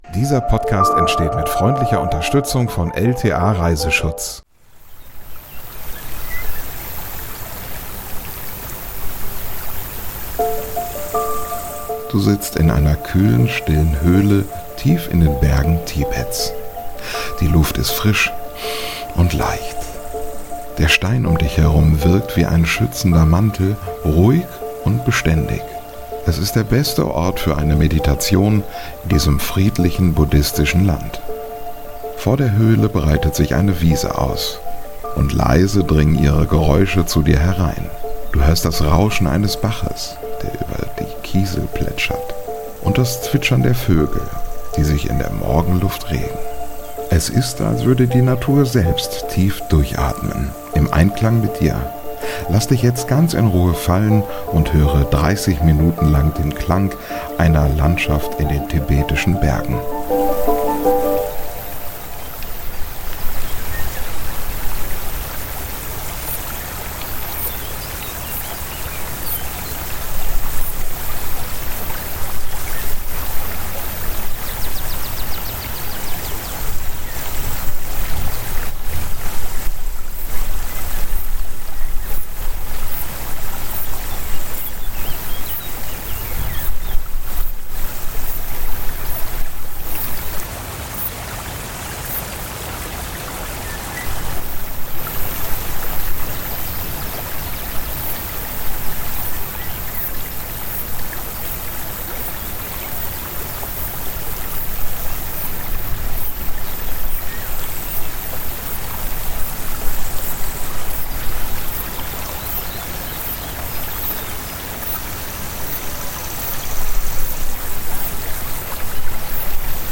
ASMR Ein Ort in der Natur zum Meditieren: Ambient 3D-Sound zum Einschlafen ~ Lieblingsreisen - Mikroabenteuer und die weite Welt Podcast
Du hörst das Rauschen eines Baches, der über Kiesel plätschert, und das Zwitschern der Vögel, die sich in der Morgenluft regen.
Lass dich jetzt ganz in diese Ruhe fallen und höre 30 Minuten lang den Klang einer Landschaft in den tibetischen Bergen.